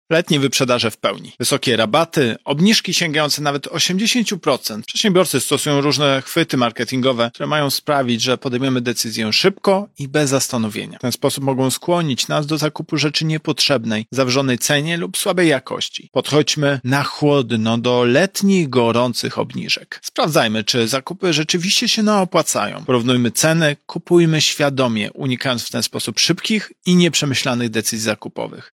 Mówi Tomasz Chróstny, prezes Urzędu Ochrony Konkurencji i Konsumentów.